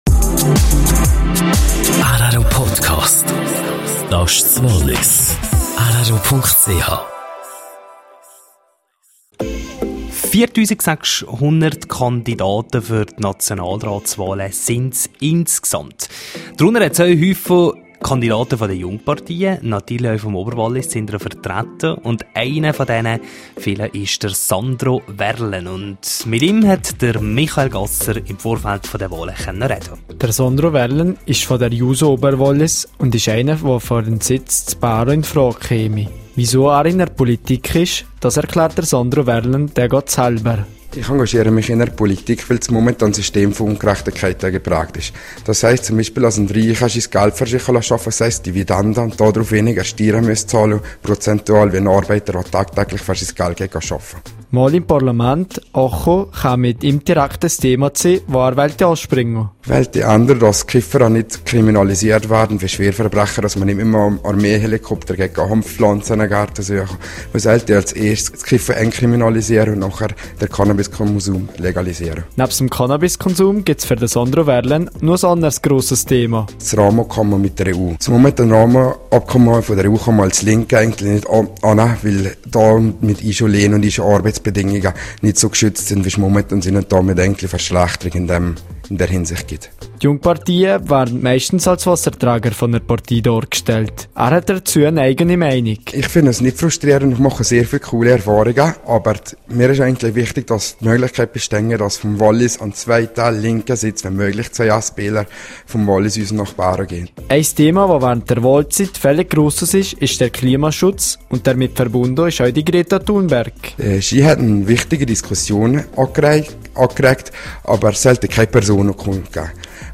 Sie wollen mehr sein als nur Wasserträger. Jungpolitiker, welche für den Nationalrat kandidieren.